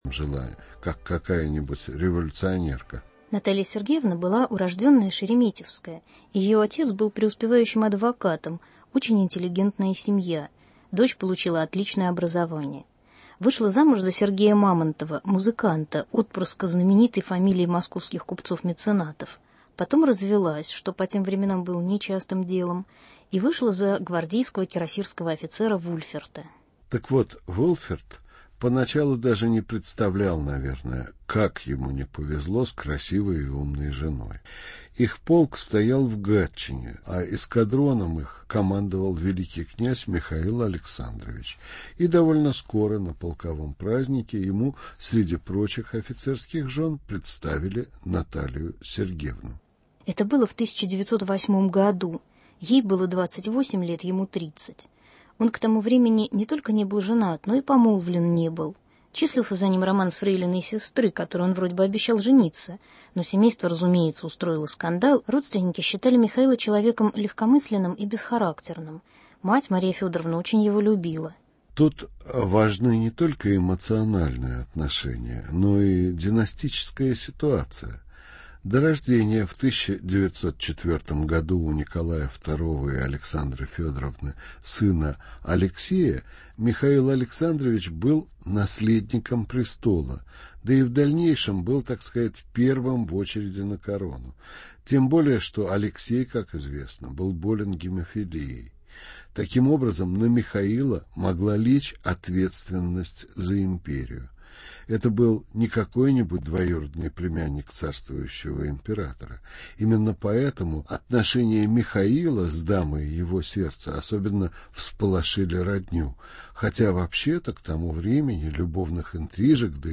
Рассказ о романе и судьбе брата Николая Второго великого князя Михаила Александровича и графини Брасовой. Гость передачи - директор Государственного архива РФ Сергей Мироненко.